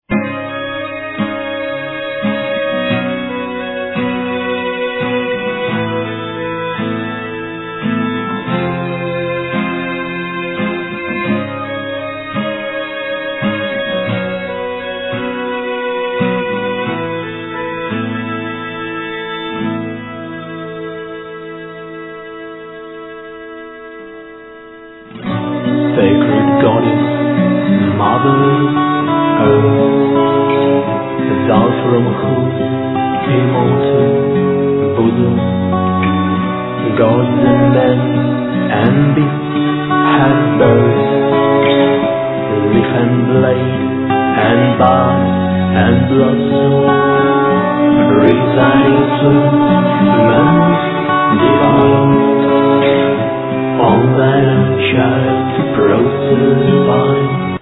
Voices
Keyboards, Flute
Cello
Violin
Guitars
Voices, Sounds
Voice, Guitar